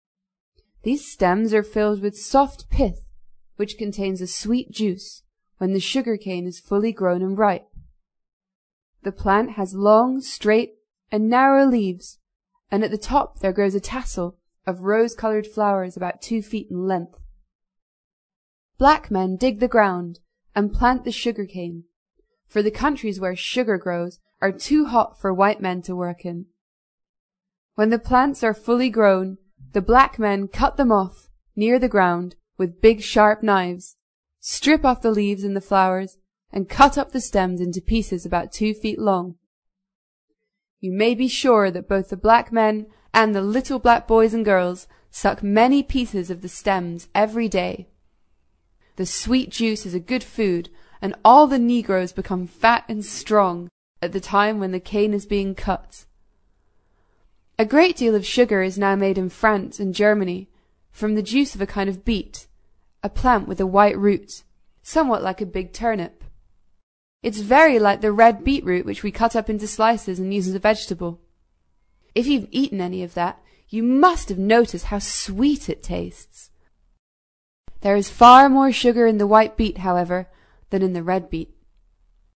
在线英语听力室英国学生科学读本 第40期:甘蔗(2)的听力文件下载,《英国学生科学读本》讲述大自然中的动物、植物等广博的科学知识，犹如一部万物简史。在线英语听力室提供配套英文朗读与双语字幕，帮助读者全面提升英语阅读水平。